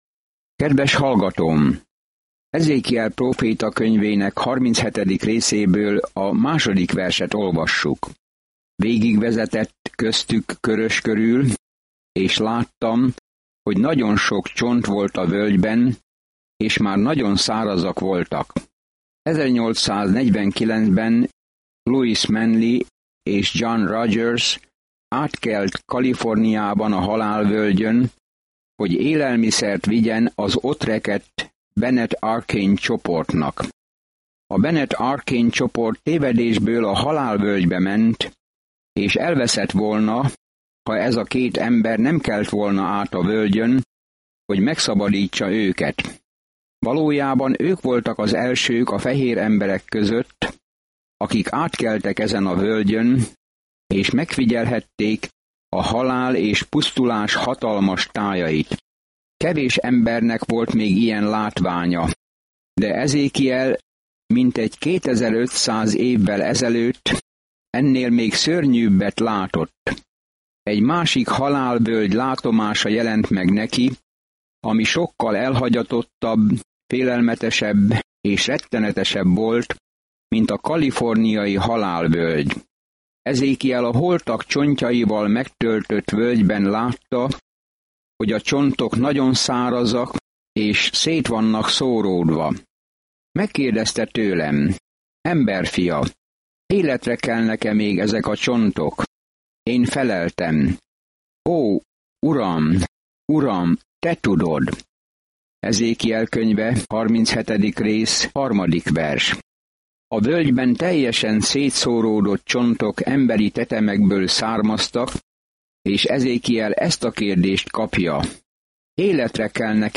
Szentírás Ezékiel 37:2-28 Nap 21 Olvasóterv elkezdése Nap 23 A tervről Az emberek nem hallgattak Ezékiel figyelmeztető szavaira, hogy térjenek vissza Istenhez, ezért ehelyett az apokaliptikus példázatokat adta elő, és ez meghasította az emberek szívét. Napi utazás Ezékielben, miközben hallgatja a hangos tanulmányt, és olvassa kiválasztott verseket Isten szavából.